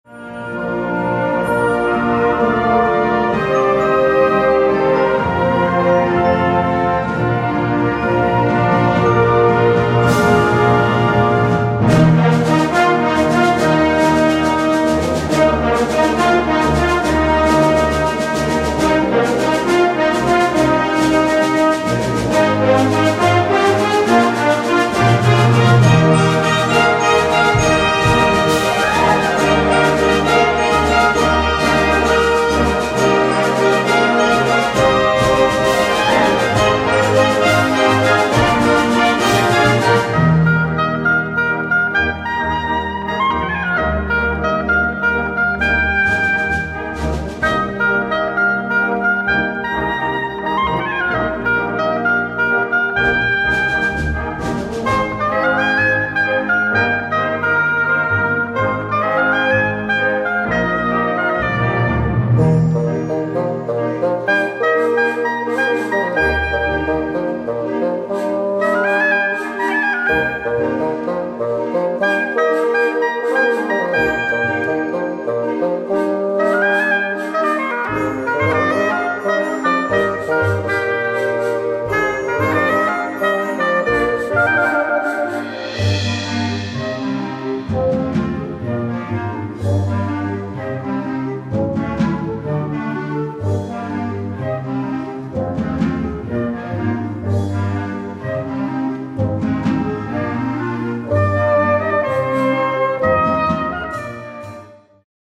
Besetzung: Blasorchester
Solo für Oboe und Fagott.